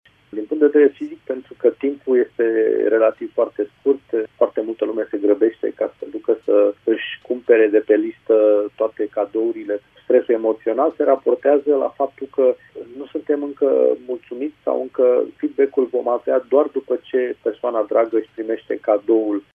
psiholog clinician